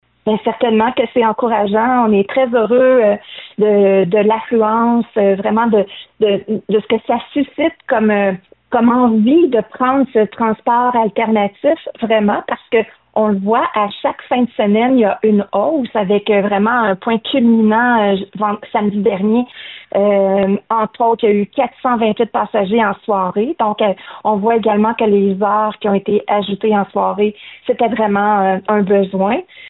Pour la mairesse de Bécancour, Lucie Allard, ces chiffres sont plutôt encourageants.